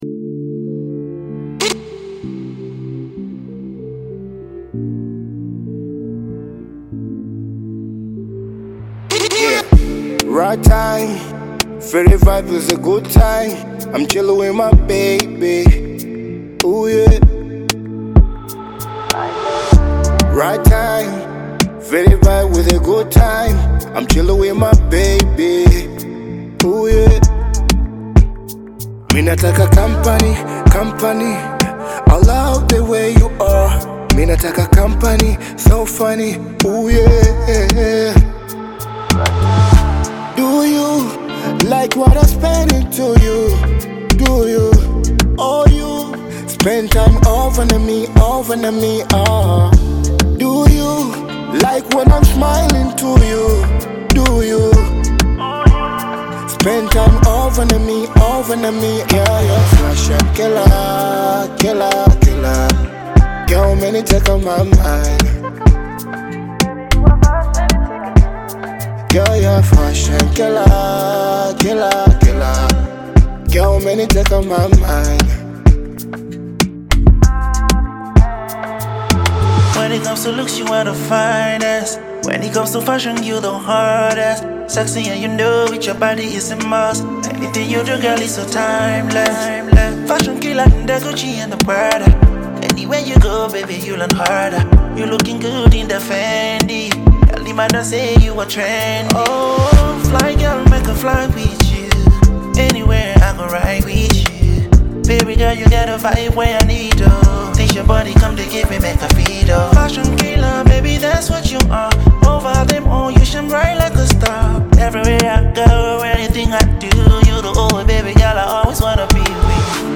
Tanzanian bongo flava recording artist
African Music